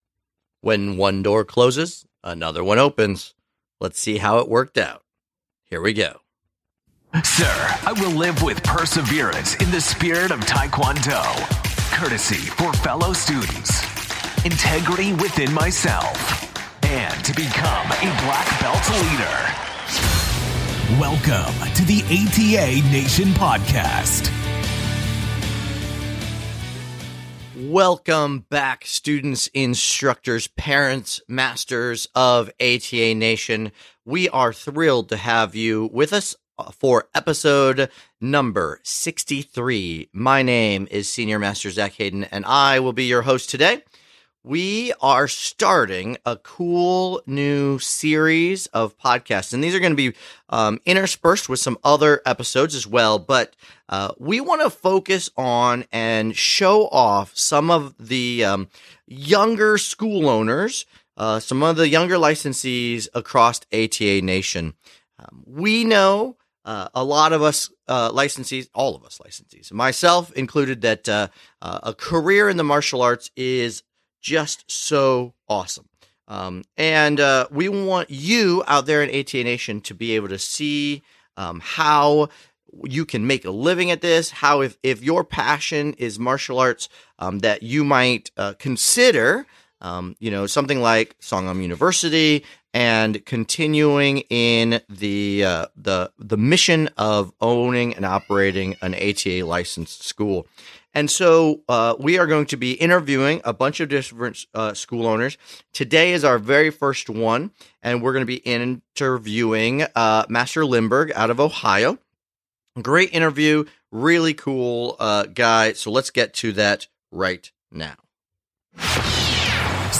This is the first episode in a series of interviews we're doing with young ATA Licensees across ATA Nation.